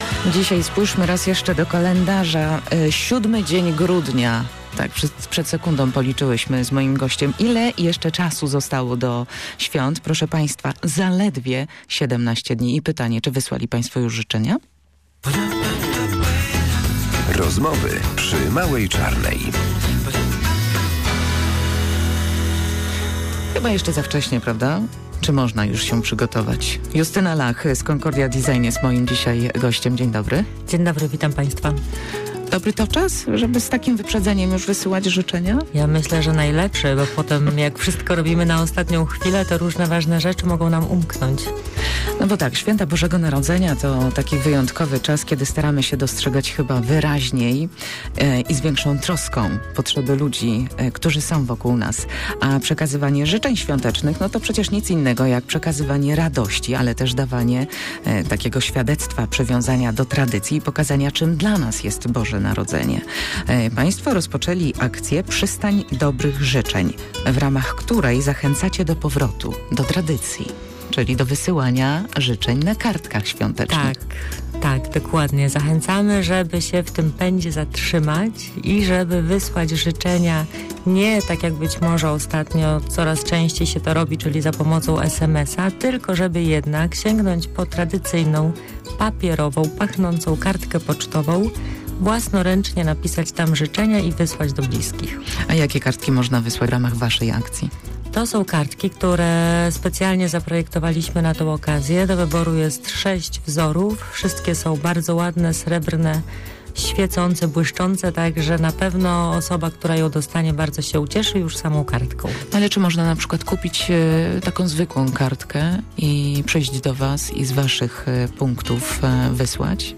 rozmawiała z gościem